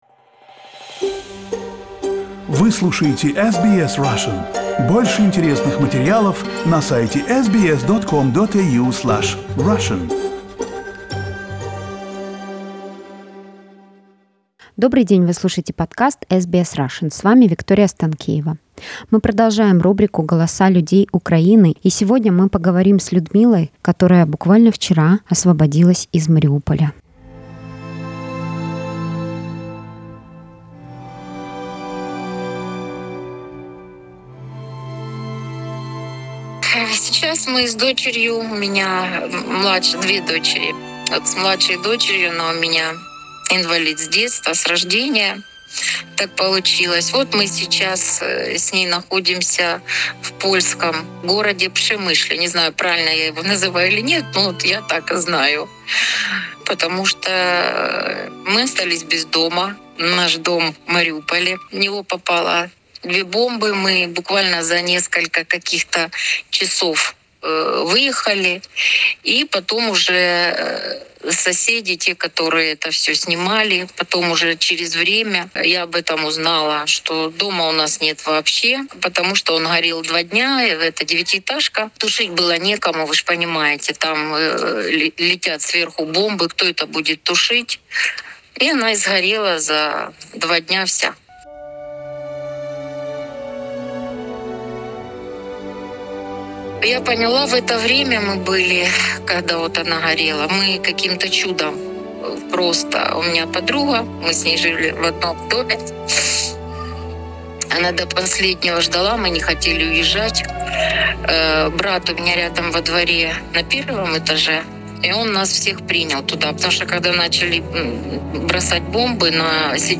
В аудио и текстовых сообщениях жители Украины рассказывают о том, на что стала похожа их жизнь в последние дни.